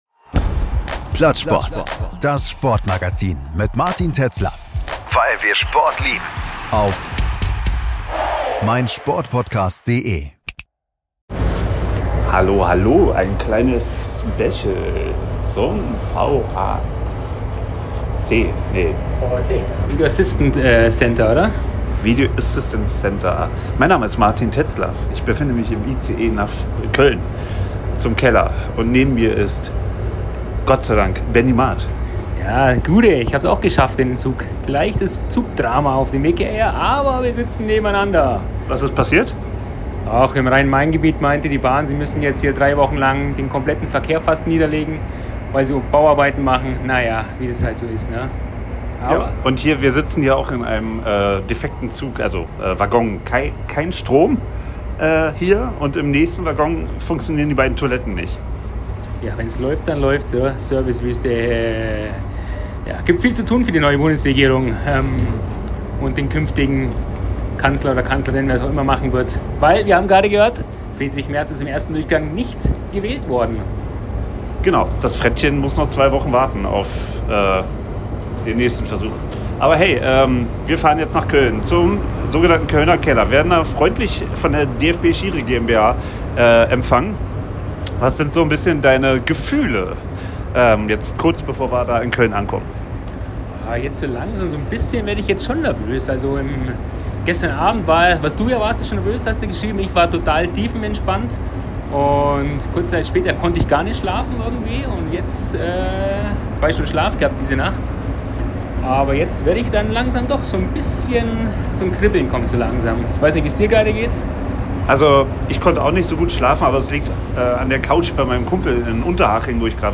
Wir haben im Zug, quasi vor dem Kölner Dom und auf dem Umstieg zurück nach Hause am Airport in Frankfurt unsere Erwartungen gebündelt und sprechen im Nachgang sehr begeistert und dennoch reflektiert über unsere Eindrücke.